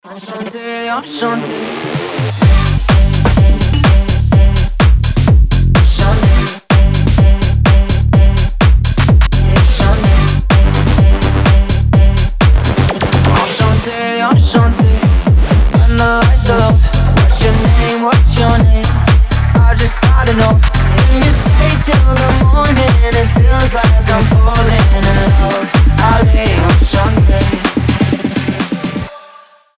Tech House
house
ремиксы